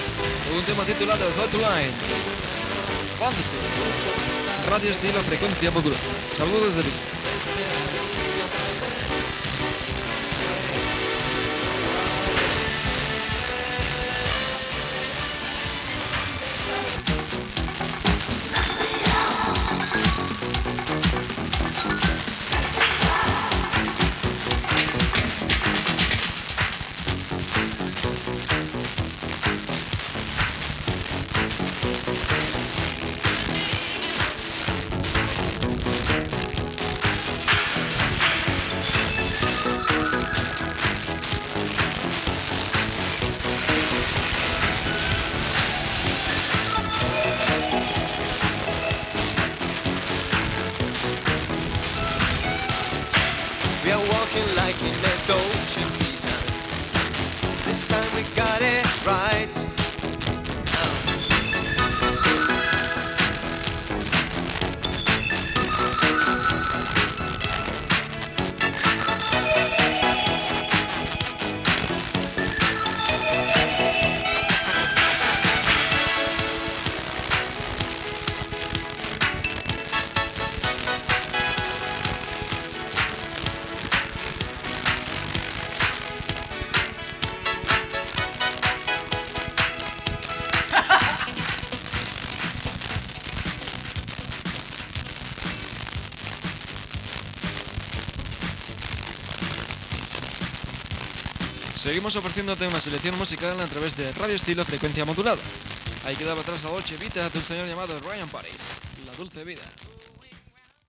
eb15b95ef1be72873e8e561b745cb9ac53eba662.mp3 Títol Radio Estilo Emissora Radio Estilo Titularitat Tercer sector Tercer sector Musical Descripció Identificacions i temes musicals.
Banda FM